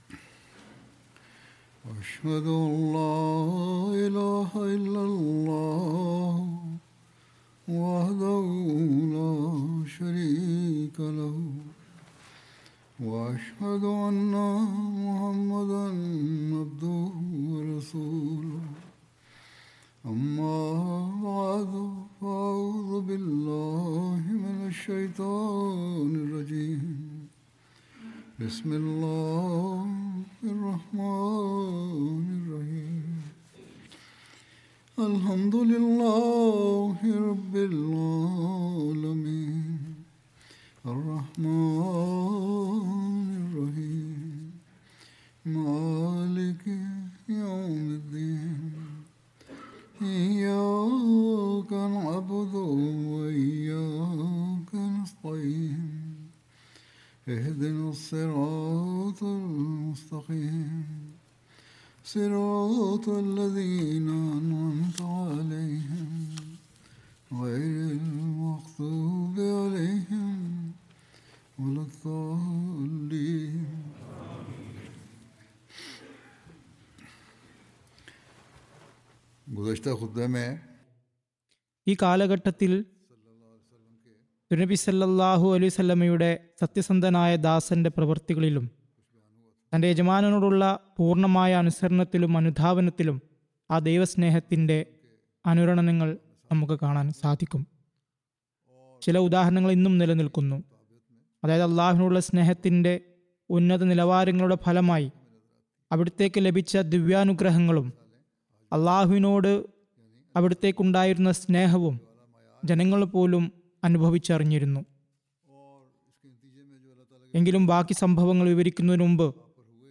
Malayalam Translation of Friday Sermon delivered by Khalifatul Masih